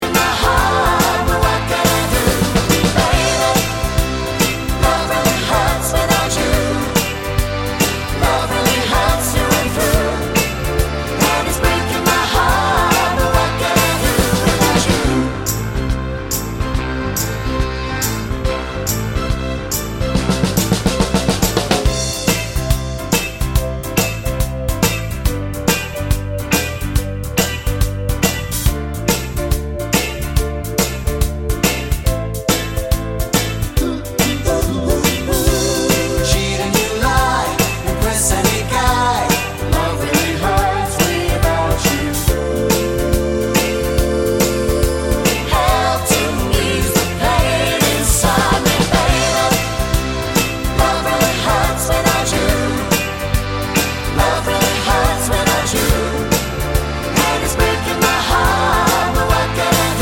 Five Semitones Down Pop (1970s) 3:06 Buy £1.50